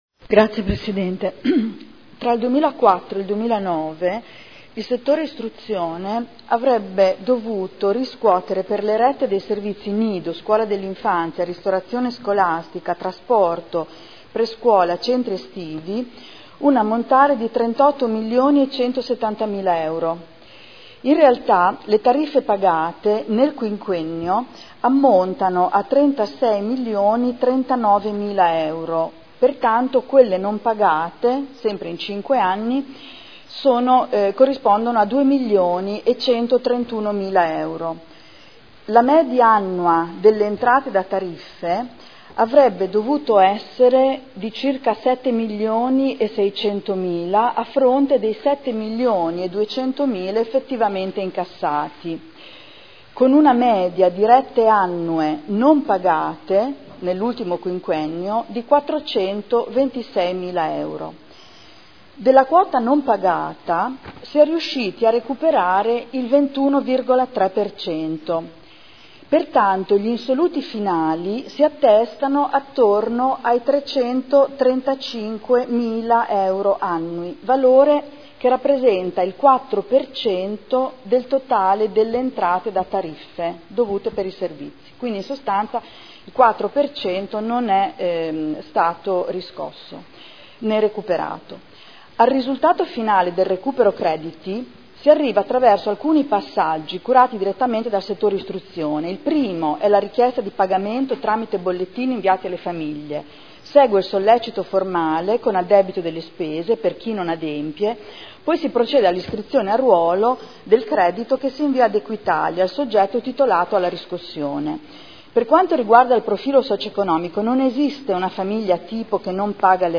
Adriana Querzè — Sito Audio Consiglio Comunale